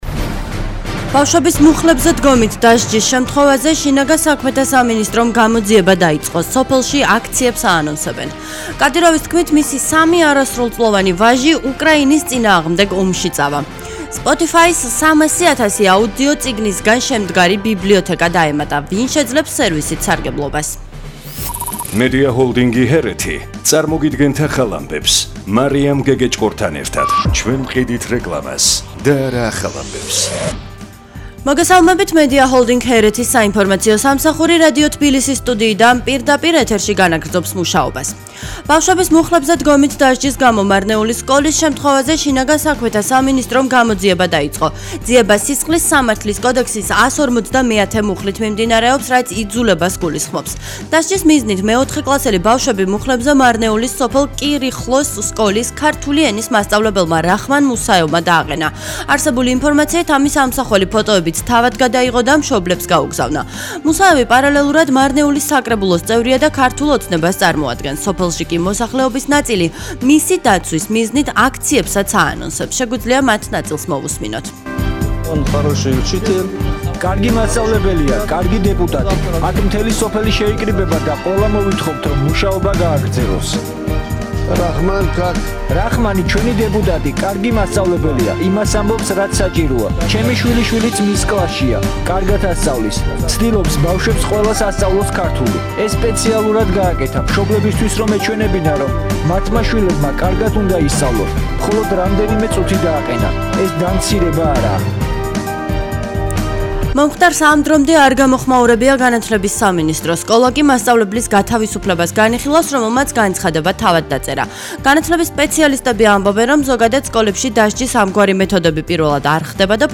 ახალი ამბები 14:00 საათზე